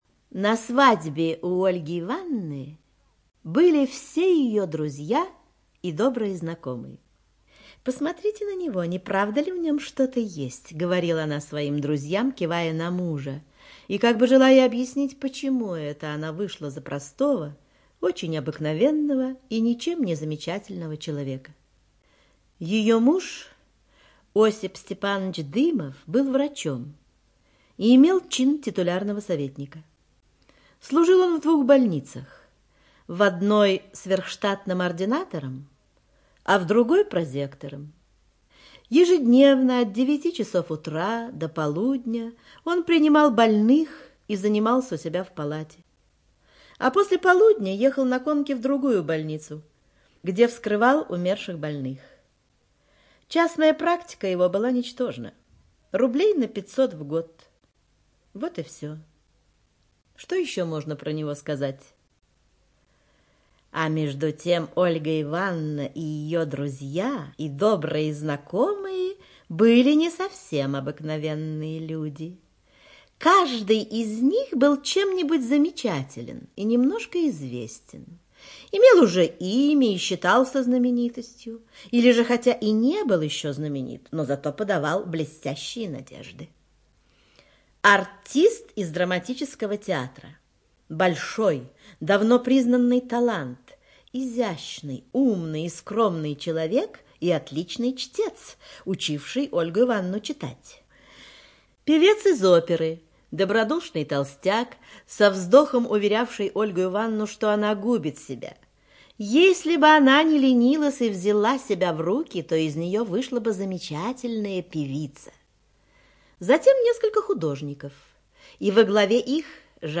ЧитаетЦеликовская Л.